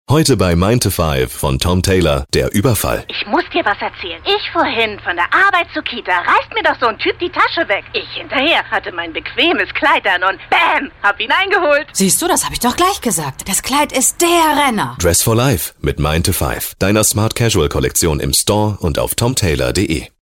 Radiowerbung: Radiospot regional DTM